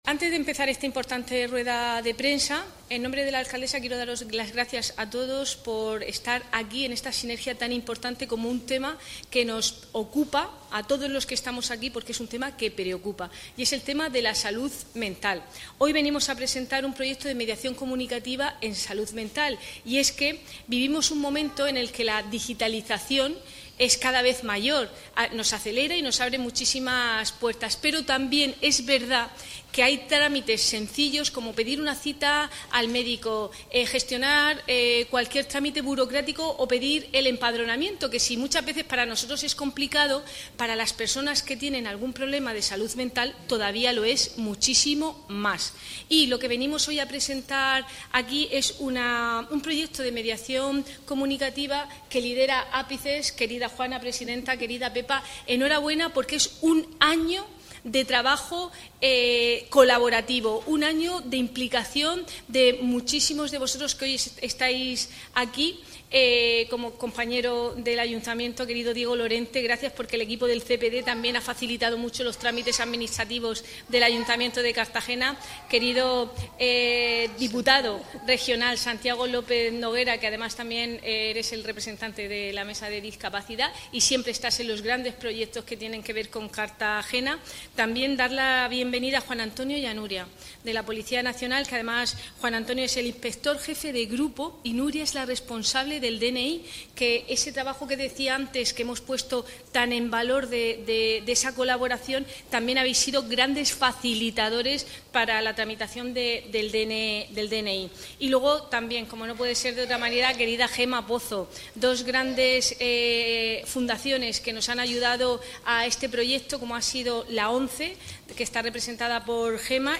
Audio: Presentaci� de las Gu�as de Mediaci�n Comunicativa de �pices (MP3 - 6,75 MB)